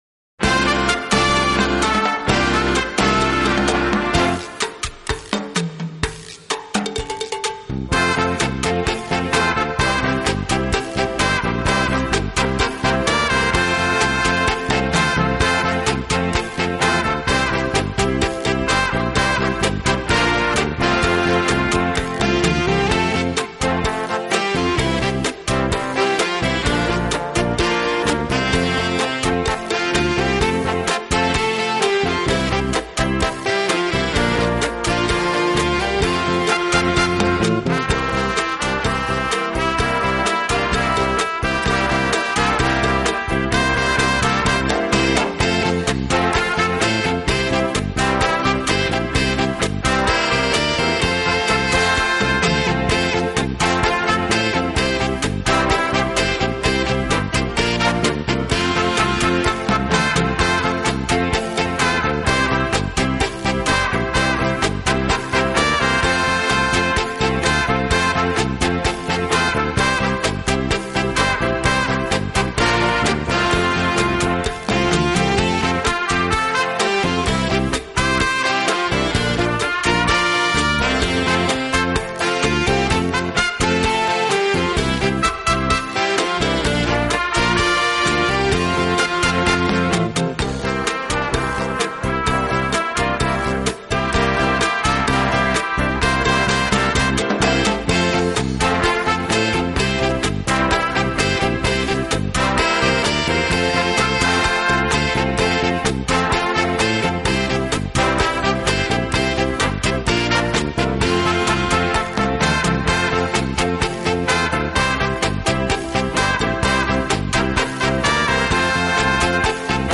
舞曲著名，曾多次在国际标准舞世界锦标赛担任音乐和节奏定标。